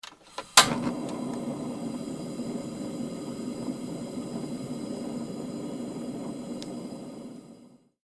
カセットコンロ.mp3